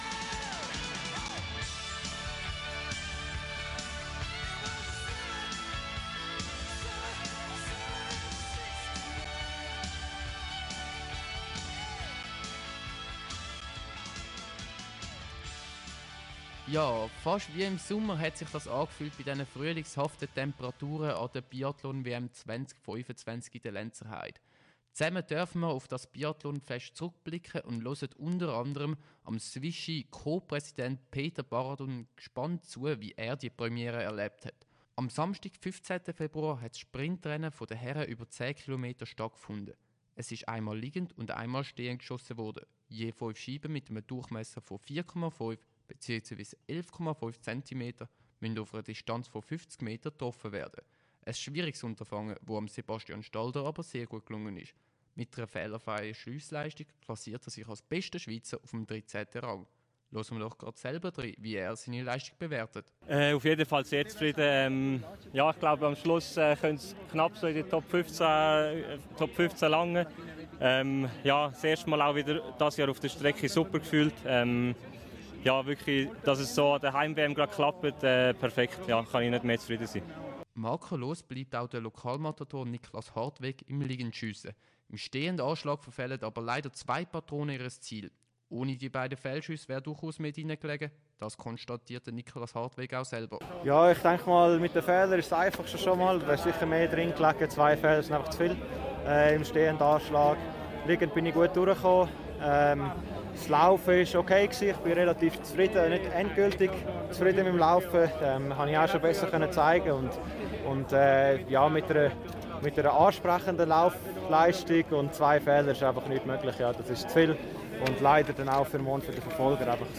Ich durfte den Sprint der Männer am 15. Februar und die beiden Staffelwettkämpfe am 22. Februar live für euch mitverfolgen und die Stimmen unserer besten Biathletinnen und Biathleten einfangen.